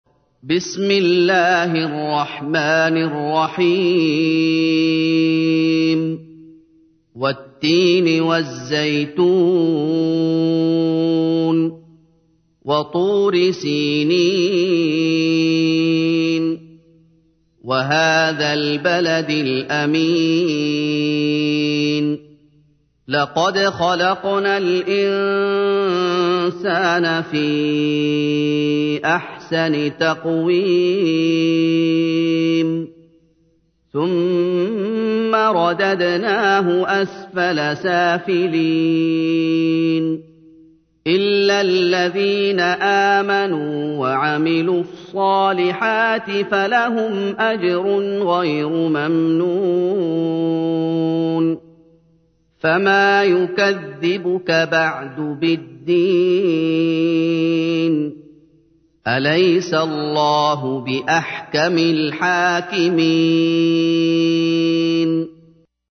تحميل : 95. سورة التين / القارئ محمد أيوب / القرآن الكريم / موقع يا حسين